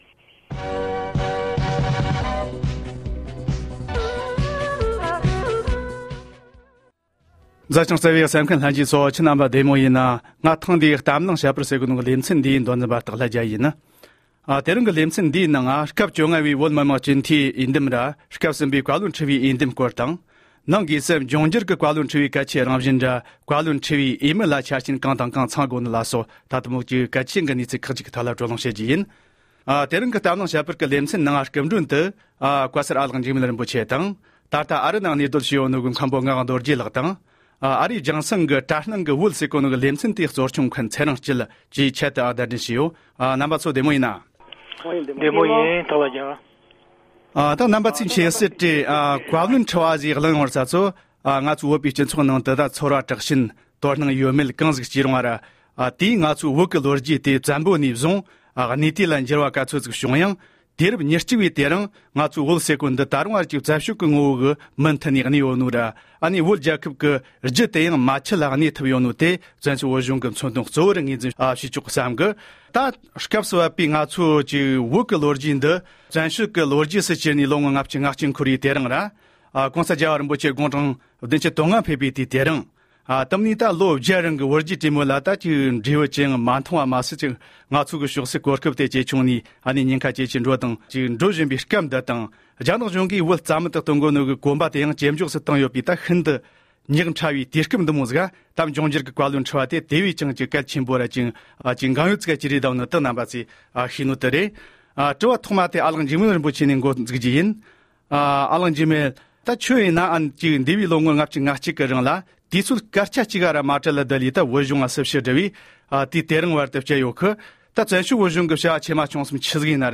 ཆབ་སྲིད་ཀྱི་ལྟ་བ་དང་དད་པ་གཉིས་མཉམ་བསྲེས་མི་བྱ་རྒྱུའི་སྐོར་བགྲོ་གླེང༌།